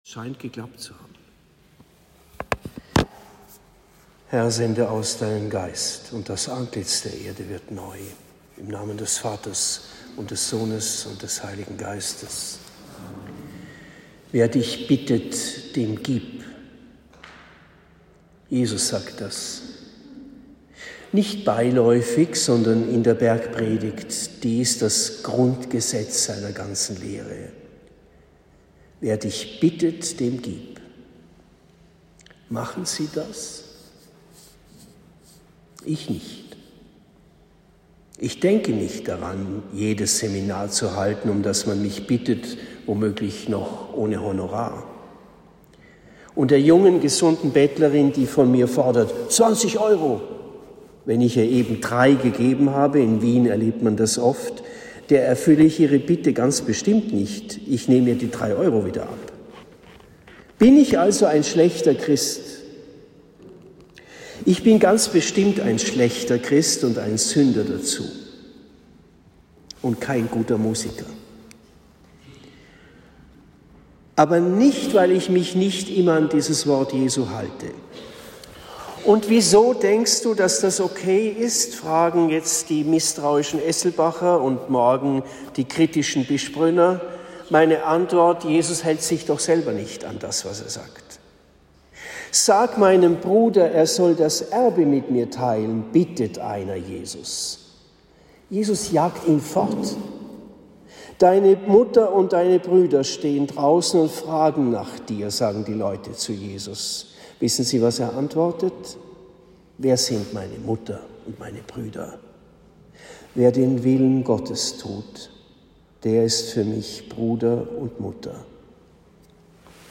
Predigt in Esselbach am 18. Februar 2023